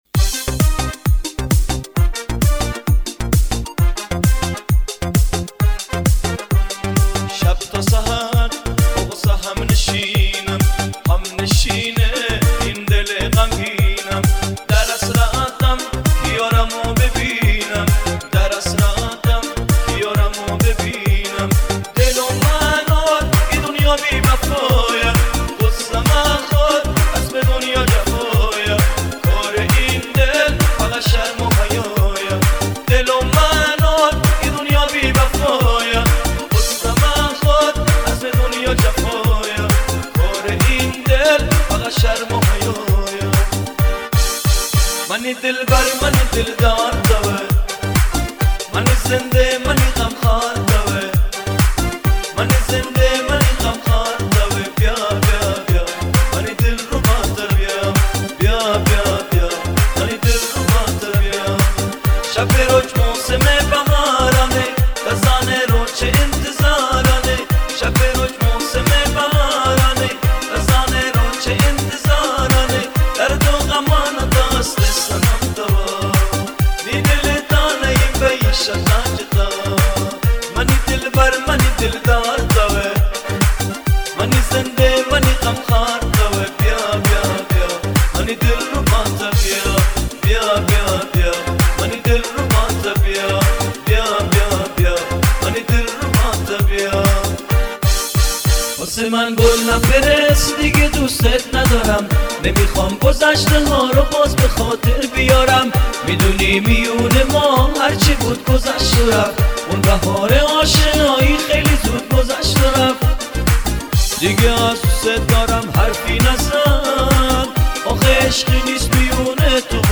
اهنگ شاد بلوچی فارسی
اهنگ شاد